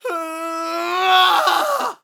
Scream Riser Sample
Categories: Vocals Tags: dry, english, fill, male, Riser, sample, Scream, Tension
TEN-vocal-fills-100BPM-A-4.wav